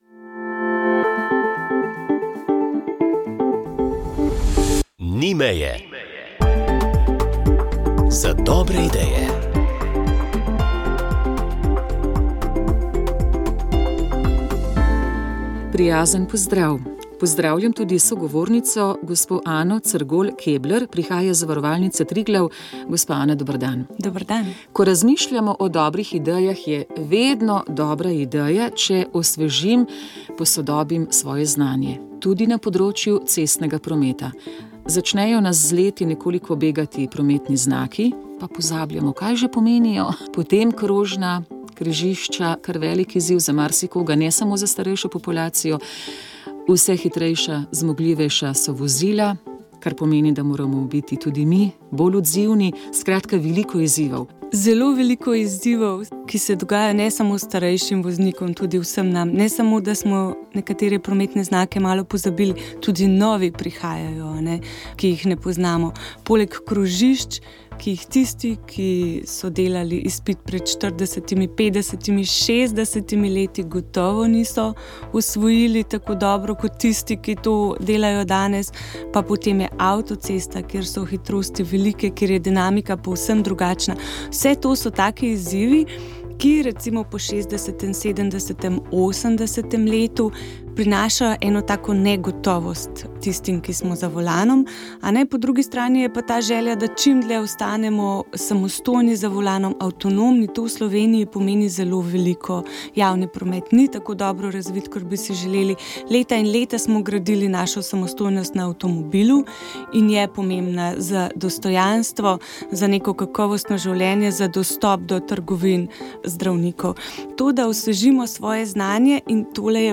Sv. maša iz cerkve Marijinega oznanjenja na Tromostovju v Ljubljani 10. 6.